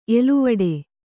♪ eluvaḍe